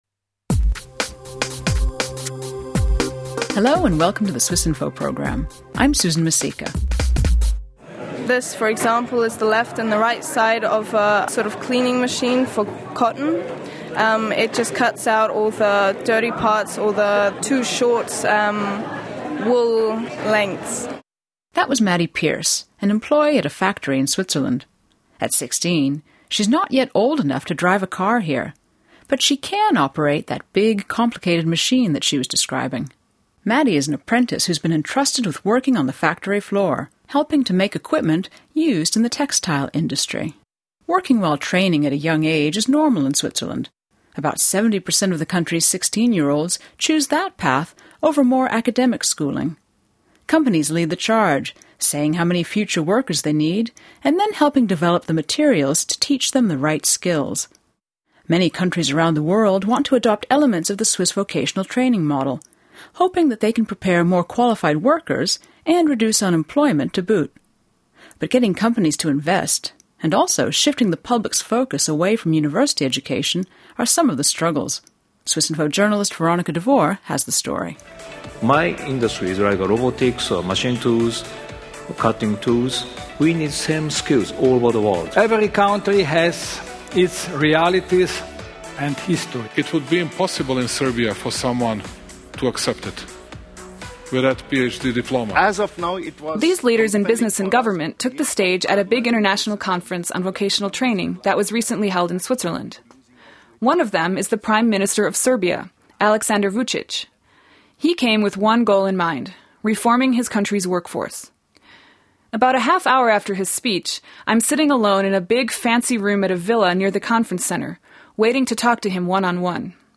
Serbia's Prime Minister and the US Ambassador to Switzerland share how both countries are looking to Switzerland for guidance on new types of career training.